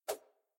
throw3.ogg